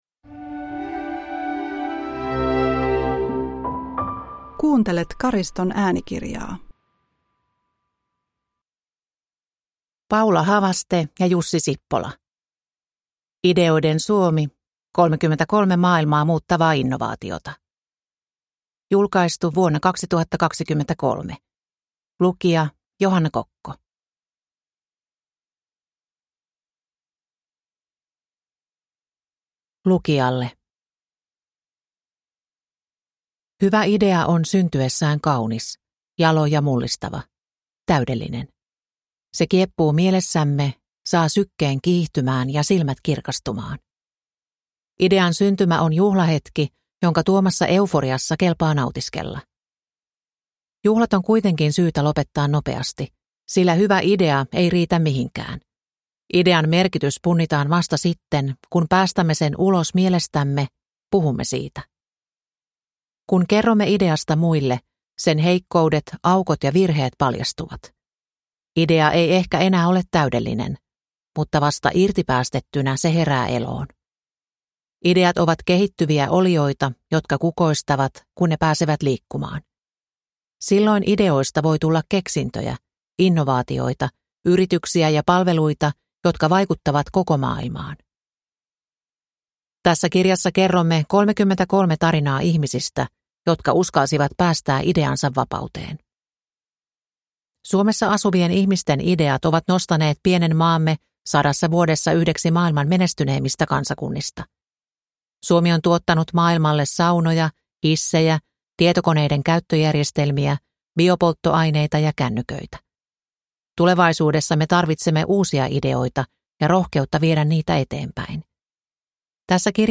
Ideoiden Suomi – Ljudbok